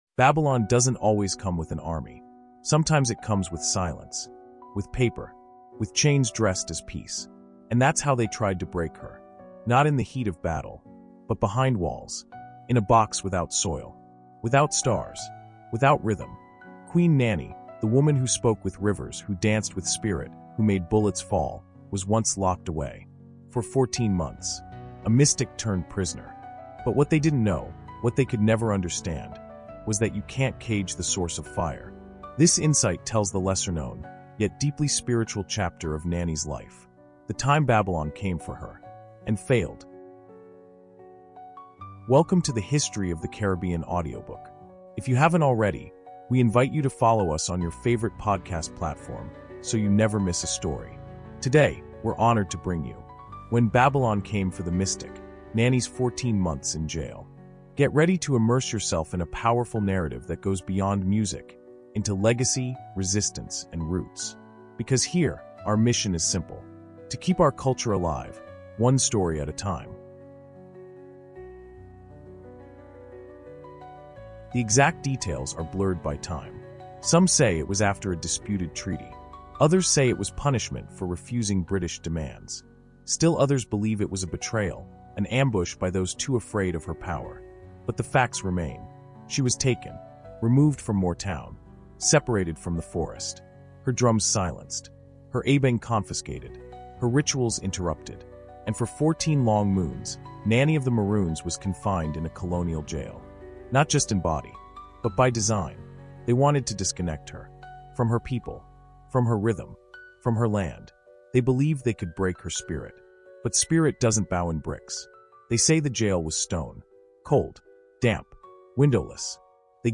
In this gripping 20-minute audio insight, we dive into Nanny’s captivity and spiritual endurance. From whispered chants to supernatural storms, her time in confinement became a masterclass in inner warfare and ancestral protection.